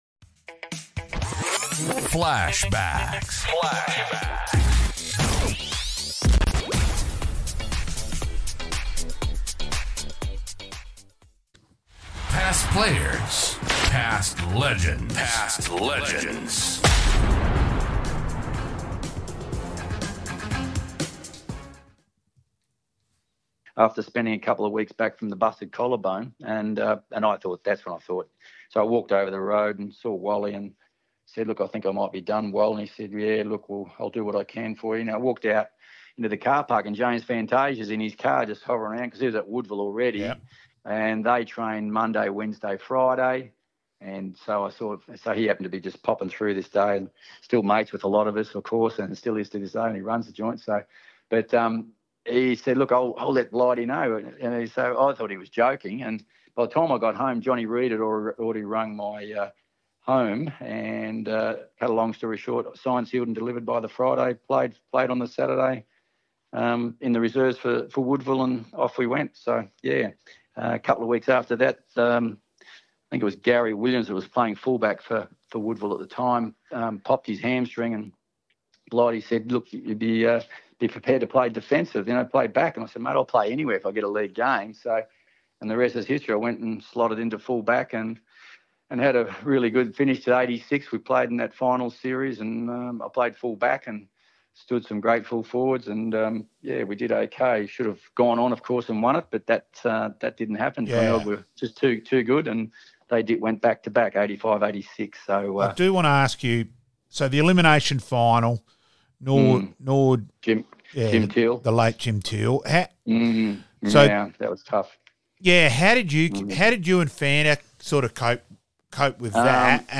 FLASHBACKS - Re-Live some of our Interviews (only) with some of our special guests